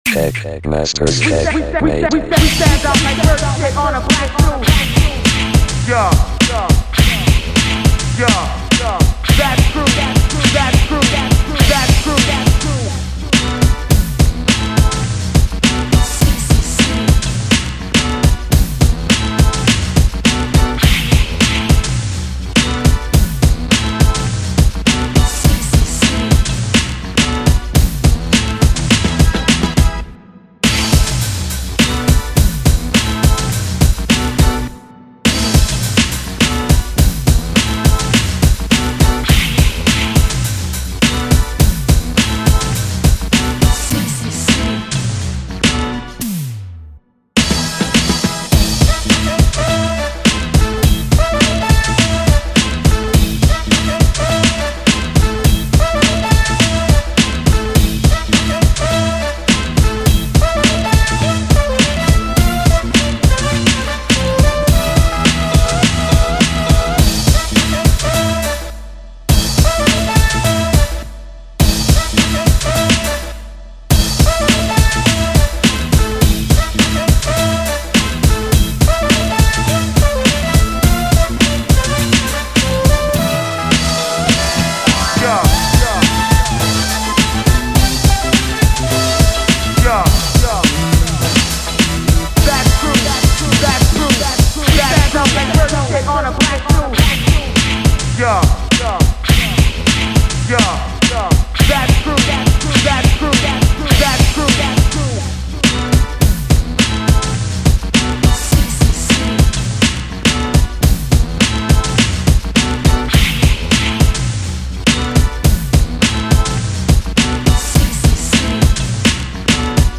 간지 나게 랩을 폭풍뱉는 트랙을 머리속에 그리며 만들었습니다.
변주가 많으니까 1분 30초까지는 들어주세요!
헐 랩탑에 마우스만으로.. 짱
오오오 진짜 로이스 충만하네요